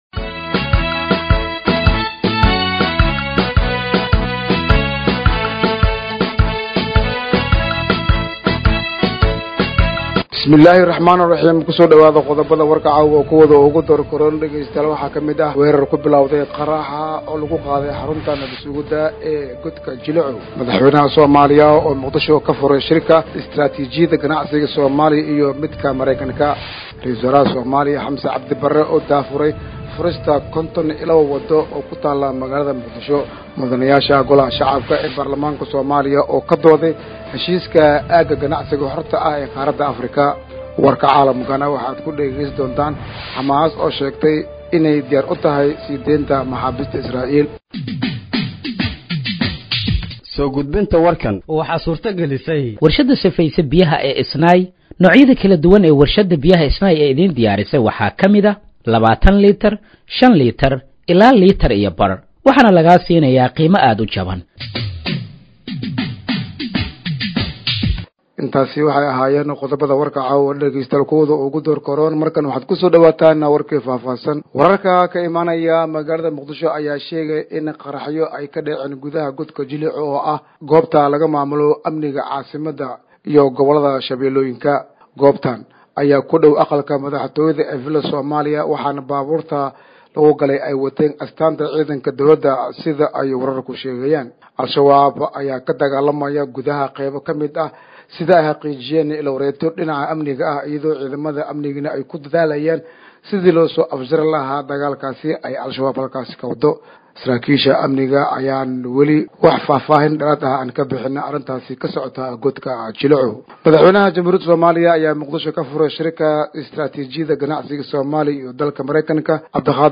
Dhageeyso Warka Habeenimo ee Radiojowhar 04/10/2025